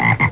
froggy.mp3